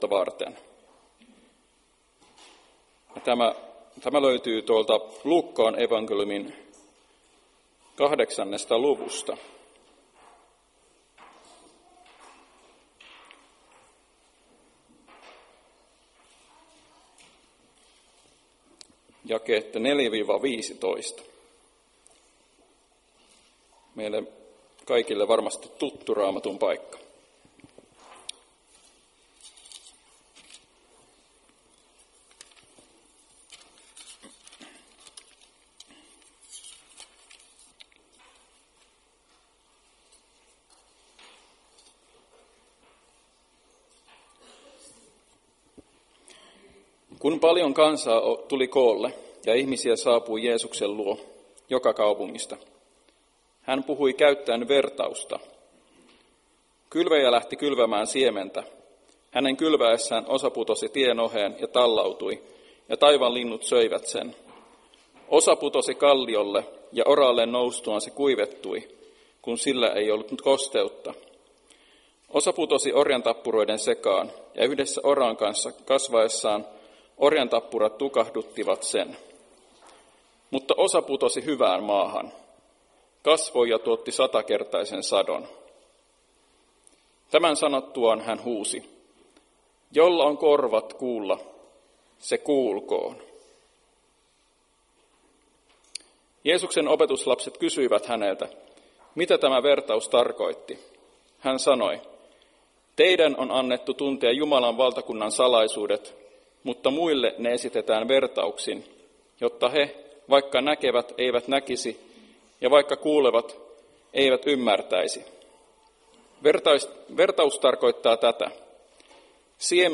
Päiväkokous 19.2.2023